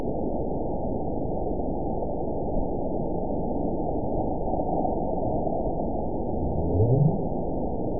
event 920516 date 03/28/24 time 19:11:09 GMT (1 year, 1 month ago) score 9.16 location TSS-AB01 detected by nrw target species NRW annotations +NRW Spectrogram: Frequency (kHz) vs. Time (s) audio not available .wav